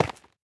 Minecraft Version Minecraft Version latest Latest Release | Latest Snapshot latest / assets / minecraft / sounds / block / dripstone / break1.ogg Compare With Compare With Latest Release | Latest Snapshot